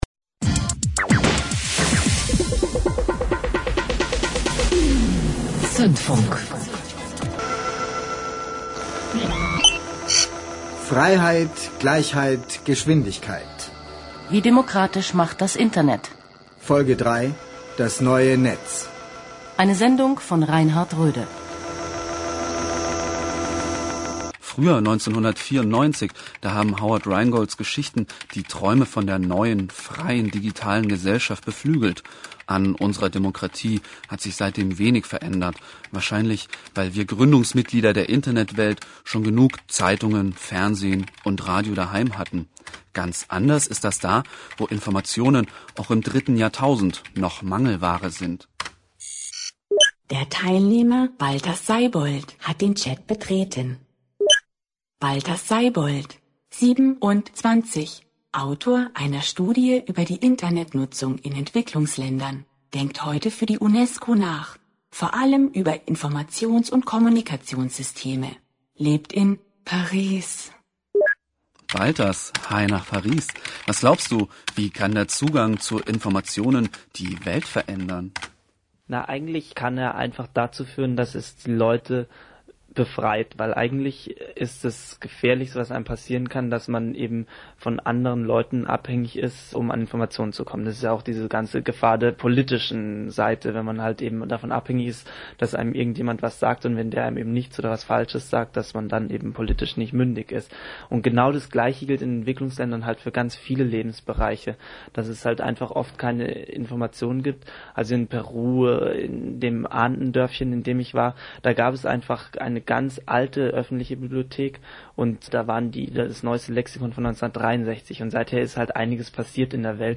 Radio-Sendung (Auszug online) und Webseite „Freiheit, Gleichheit, Geschwindigkeit: Wie demokratisch macht das Internet?“